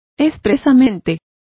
Complete with pronunciation of the translation of specially.